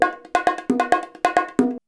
133BONG06.wav